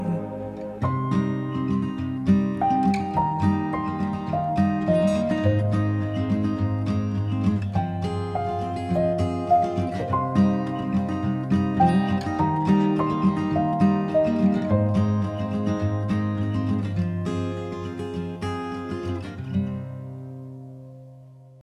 гитара
красивая мелодия
indie pop
indie folk
акустика
акустическая гитара
Романтическая спокойная мелодия